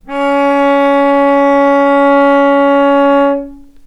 healing-soundscapes/Sound Banks/HSS_OP_Pack/Strings/cello/ord/vc-C#4-mf.AIF at bf8b0d83acd083cad68aa8590bc4568aa0baec05
vc-C#4-mf.AIF